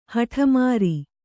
♪ haṭha māri